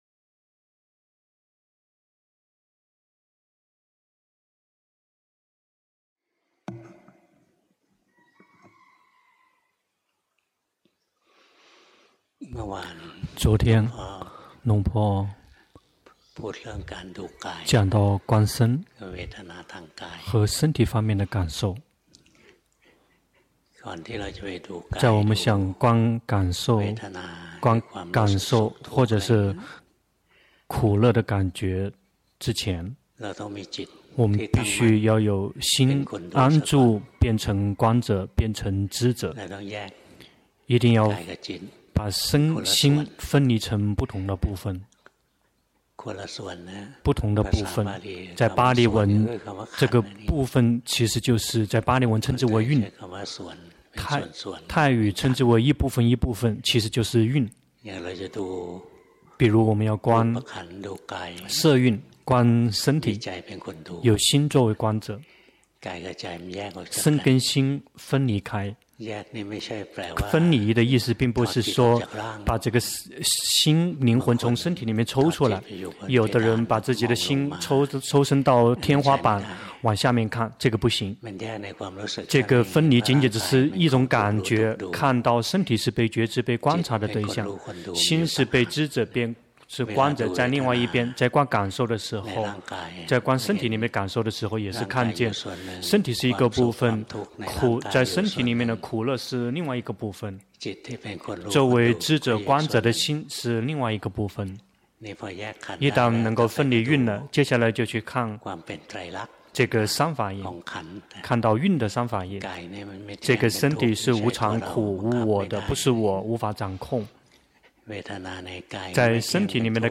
2020年10月11日｜泰國解脫園寺 同聲翻譯